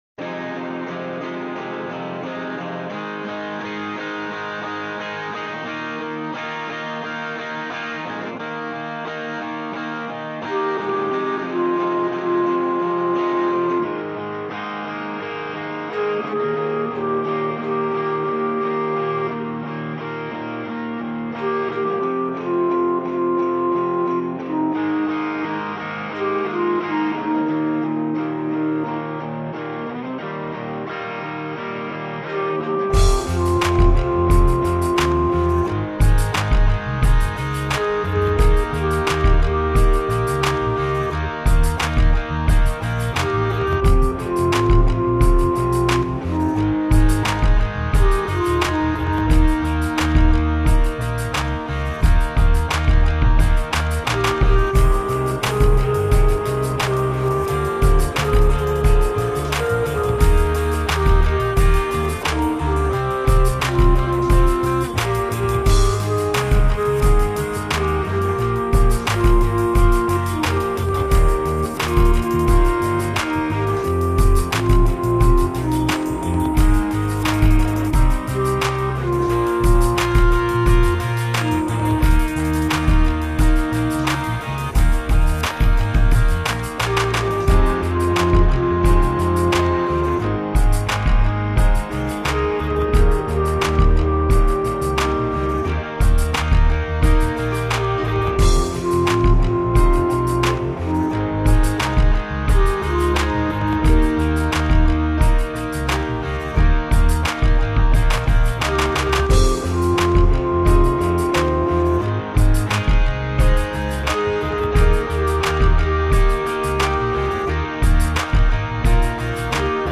A psalm-like sung prayer